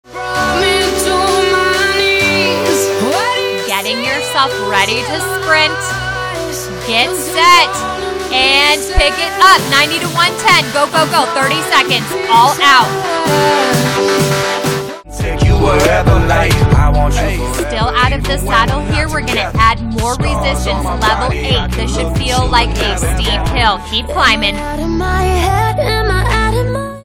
Plan to work hard and time to fly by as you go through seated/standing sprints, rolling hills and steep terrain. Music from David Guetta, Machine Gun Kelly, Nelly, and more. Note: This class contains explicit language.